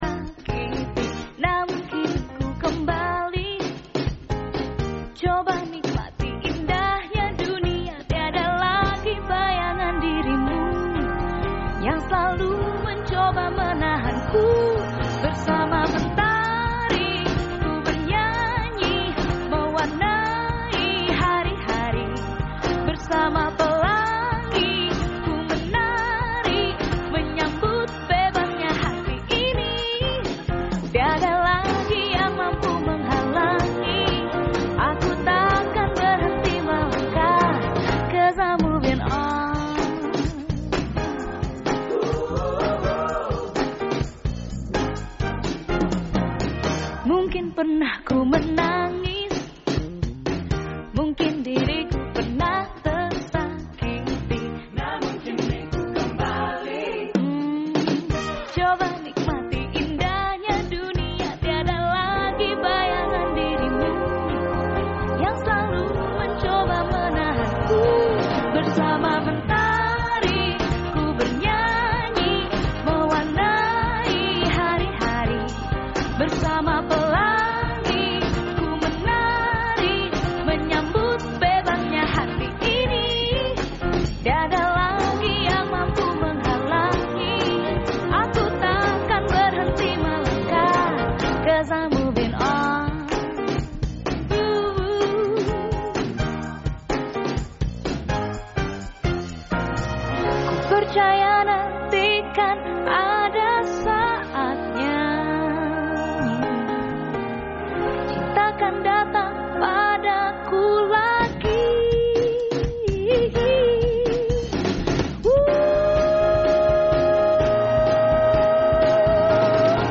Rekaman Siaran Pro 1 FM 91.1Mhz RRI Yogyakarta